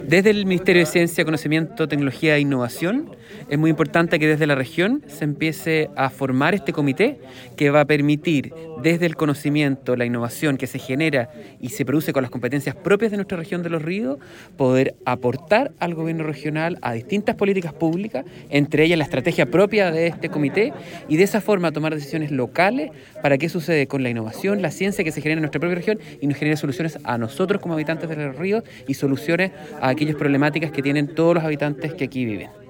Parte de las tareas que tiene el comité recién conformado, serán identificar y formular políticas y acciones que fortalezcan la ciencia, tecnología e innovación en la región. Así lo detalló el seremi (s) de Ciencias, Daniel Del Campo.